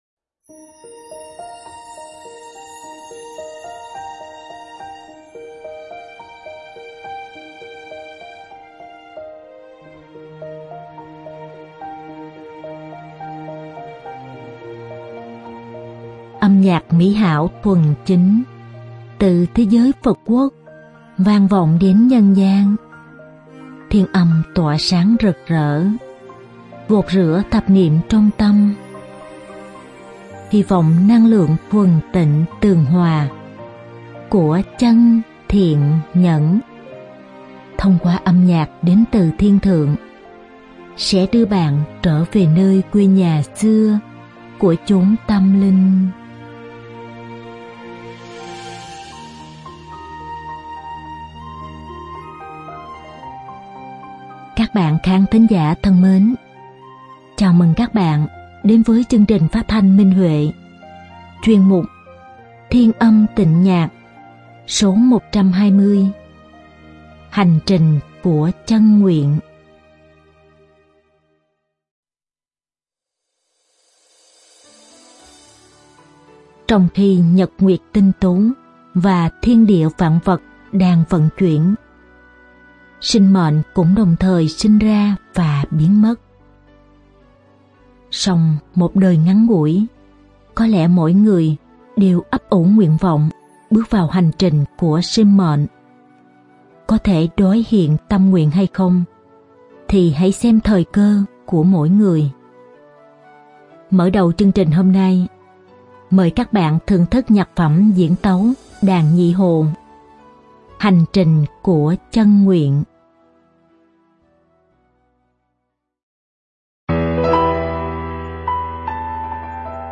Diễn tấu đàn nhị hồ
Ca khúc